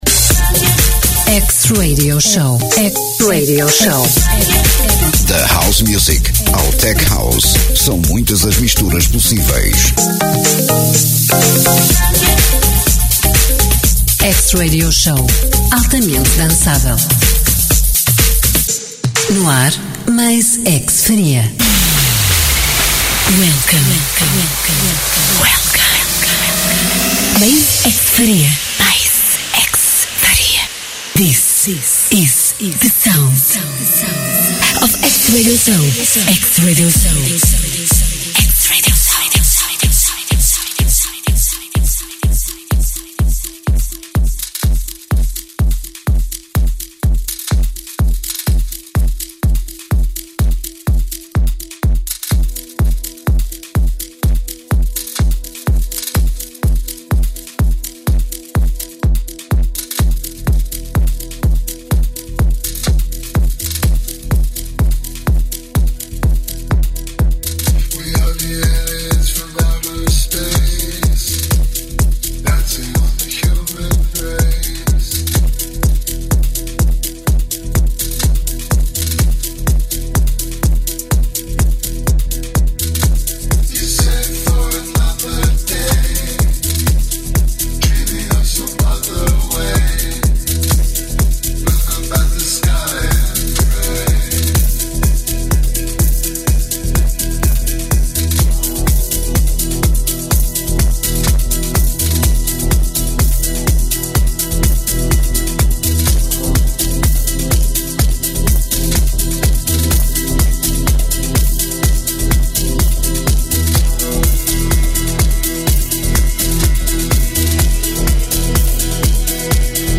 House Music in the mix